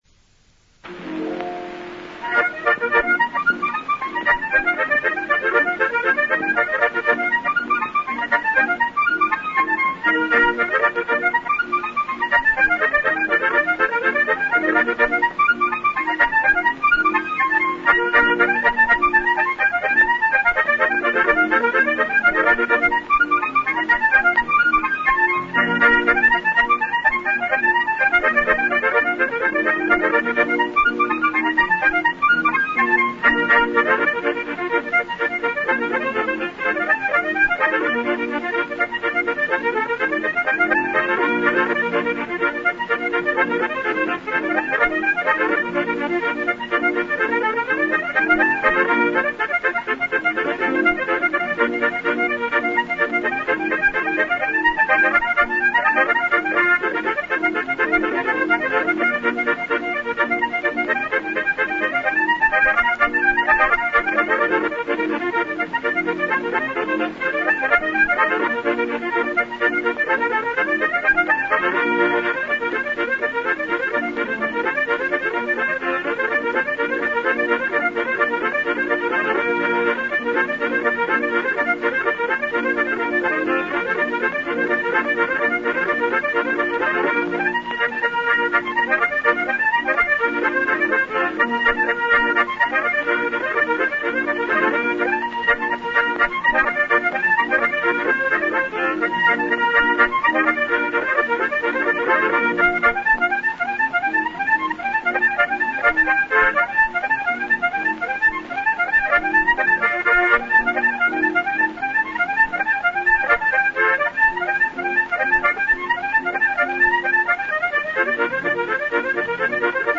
Scots Moothie